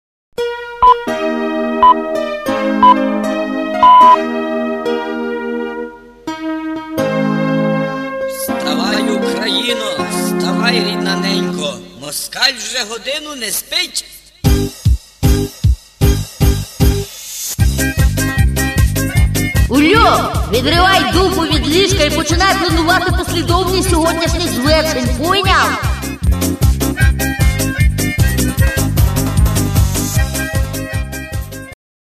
Тип: рінгтони